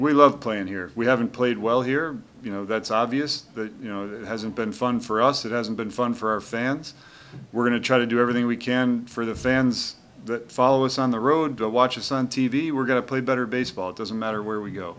Royals mgr. Matt Quatraro on finishing June with only one home win.